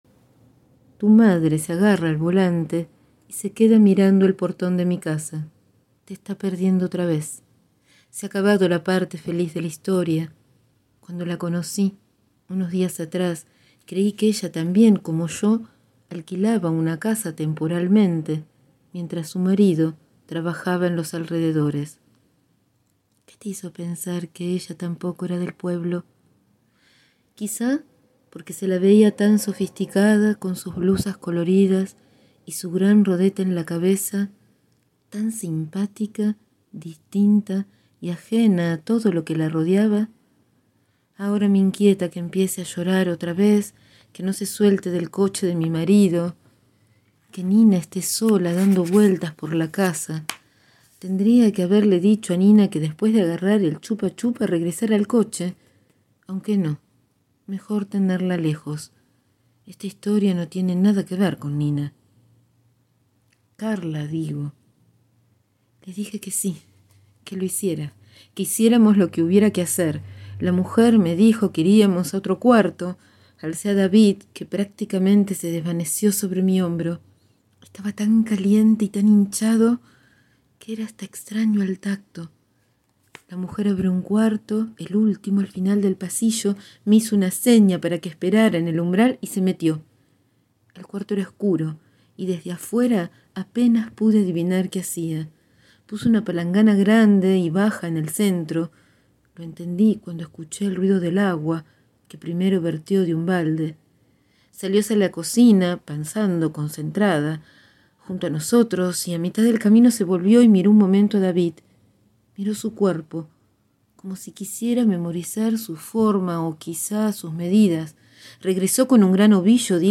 Continuamos con la lectura de esta novela.